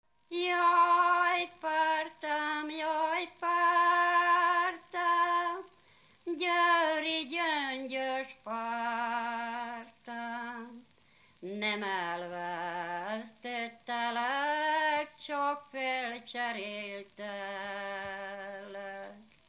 Erdély - Szilágy vm. - Kárásztelek
ének
Műfaj: Lakodalmas
Stílus: 1.1. Ereszkedő kvintváltó pentaton dallamok
Szótagszám: 6.6.6.6
Kadencia: 7 (b3) b6 1